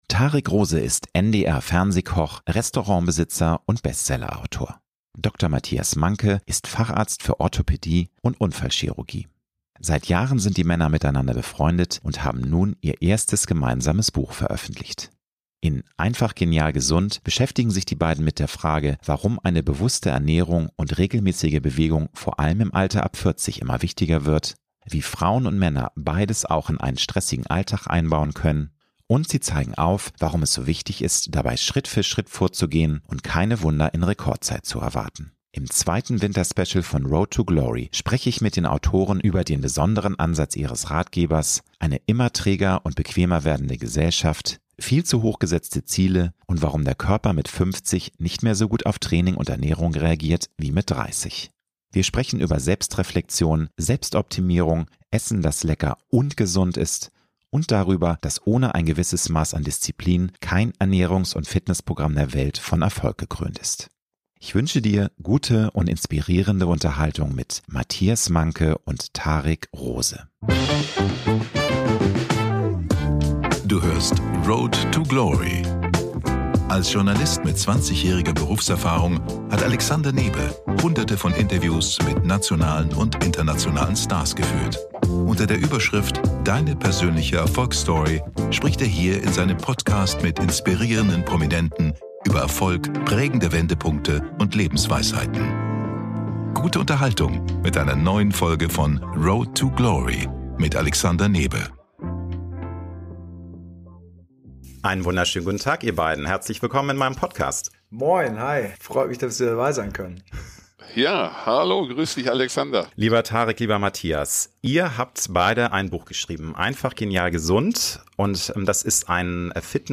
Im zweiten Winterspecial von „Road to Glory“ spreche ich mit den Autoren über den besonderen Ansatz ihres Ratgebers, eine immer träger und bequemer werdende Gesellschaft, viel zu hochgesetzte Ziele und warum der Körper mit 50 nicht mehr so gut auf Training und Ernährung reagiert wie mit 30. Wir sprechen über Selbstreflexion, Selbstoptimierung; Essen, das lecker UND gesund ist und darüber, dass ohne ein gewisses Maß an Disziplin kein Ernährungs- und Fitnessprogramm der Welt von Erfolg gekrönt ist.